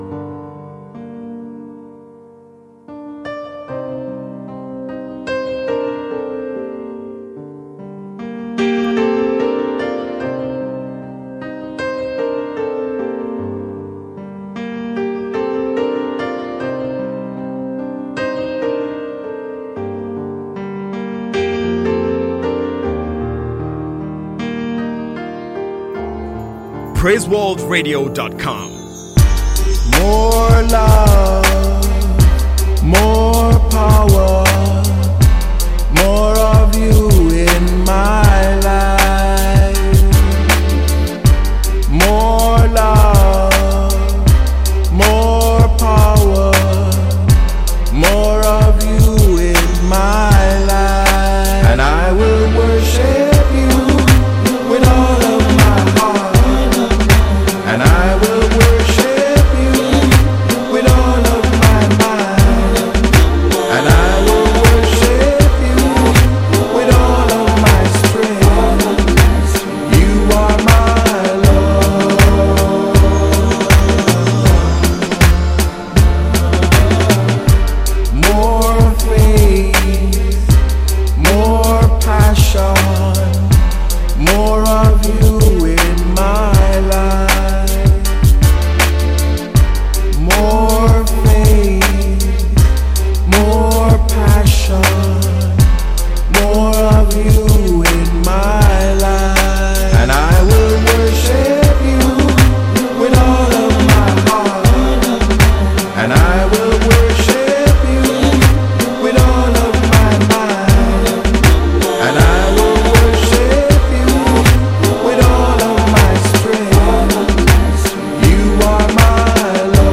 Jamaican gospel
The reggae piece